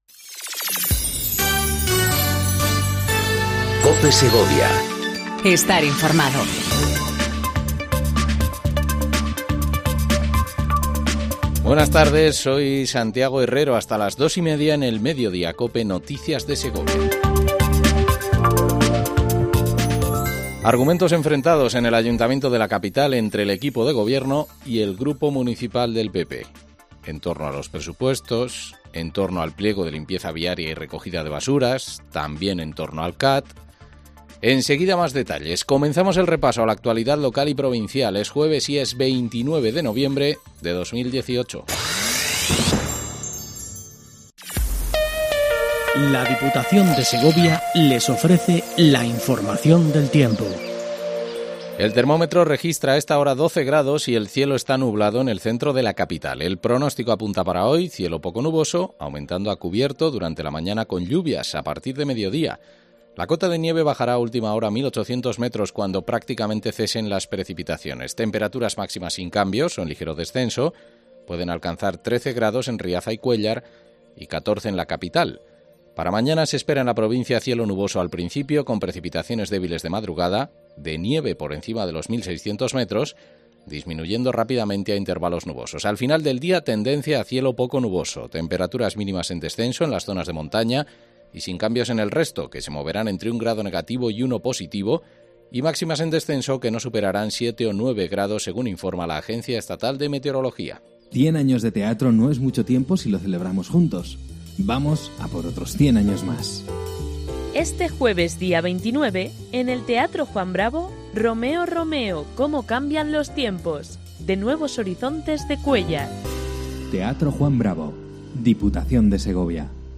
INFORMATIVO MEDIODÍA COPE SEGOVIA 14:20 DEL 29/11/18